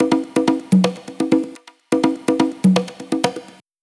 Live Percussion A 06.wav